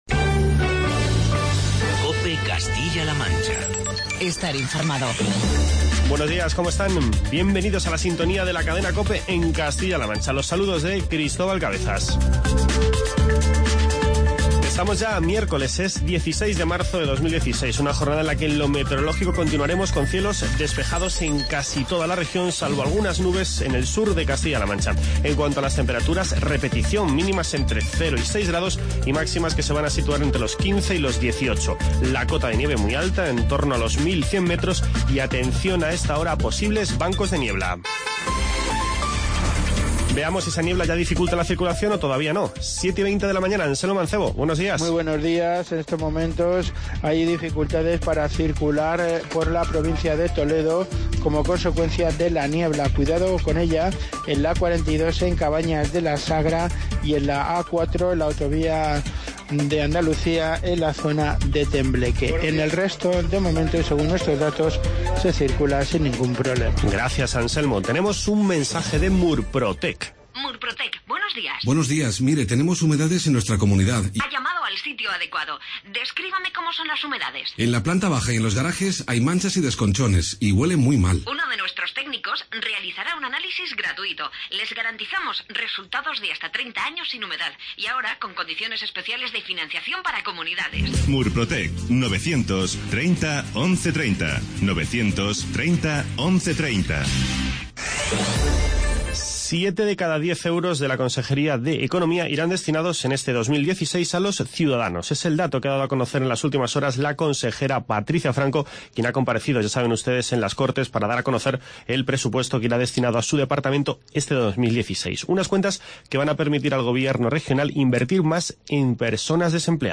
Informativo regional